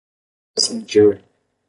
cindir a 🐌 Betekenis Sinonieme Vertalings Notes Extra tools (Engels) to split; to divide; to separate Uitgespreek as (IPA) /sĩˈd͡ʒi(ʁ)/ Etimologie (Engels) Geleen van Latyn scindō In summary Borrowed from Latin scindere.